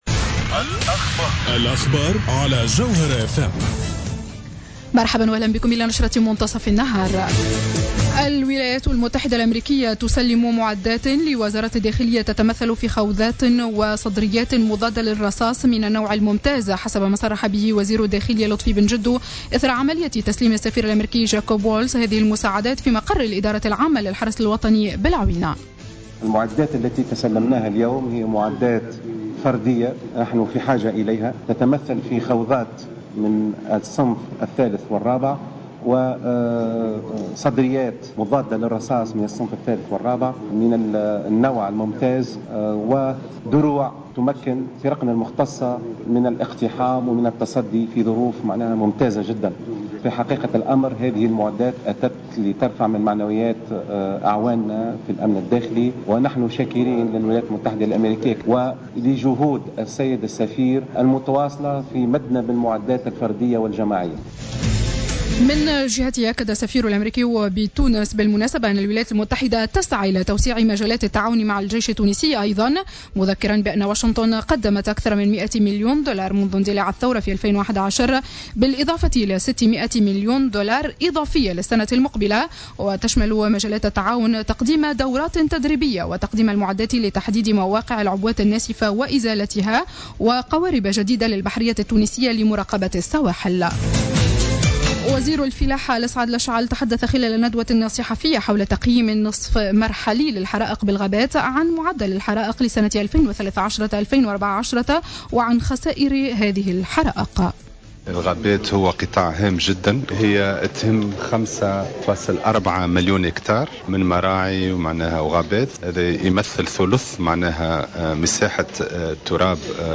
نشرة أخبار منتصف النهار ليوم الخميس 14-08-14